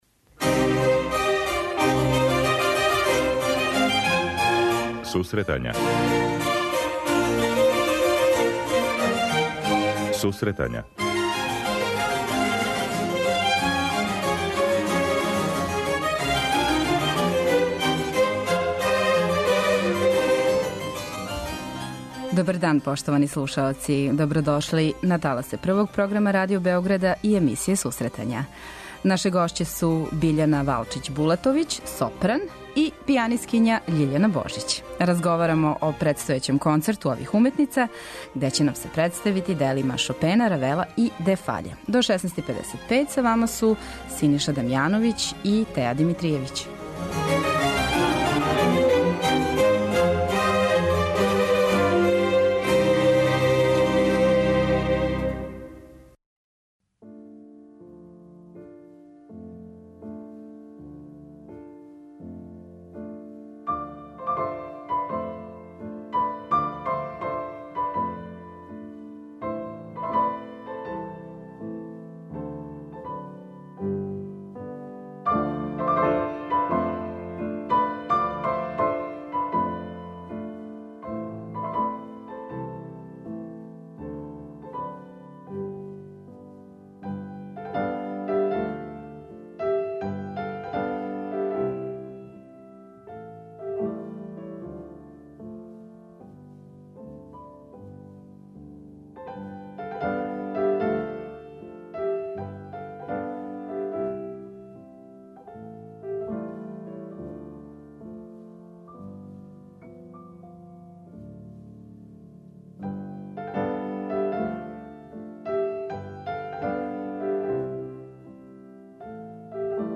преузми : 25.96 MB Сусретања Autor: Музичка редакција Емисија за оне који воле уметничку музику.